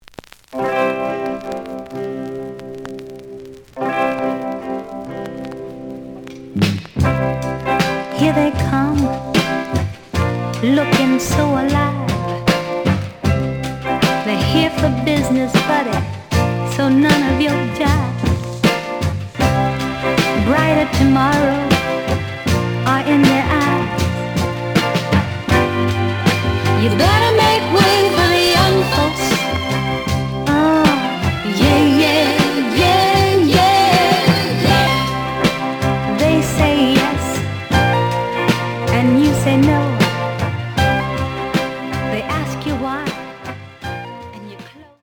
The audio sample is recorded from the actual item.
●Genre: Soul, 60's Soul
Red vinyl.)